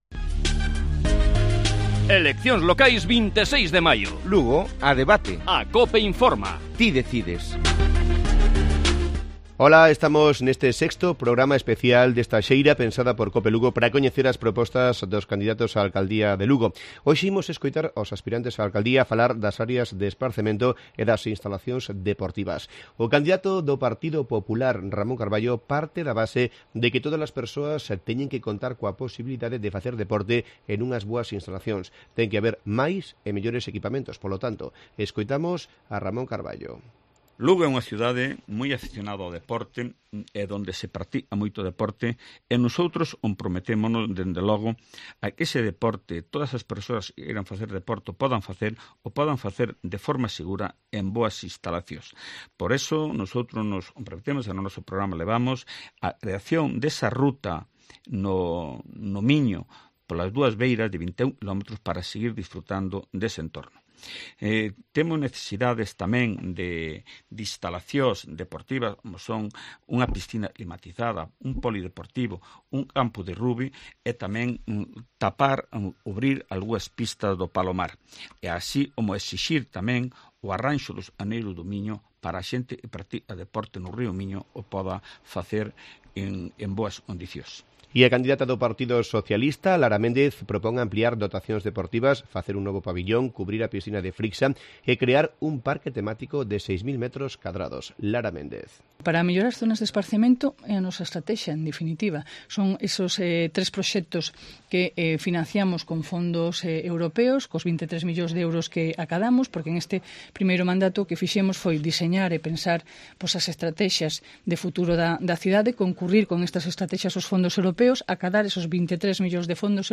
Lugo a debate: Los candidatos hablan sobre instalaciones deportivas y espacios de ocio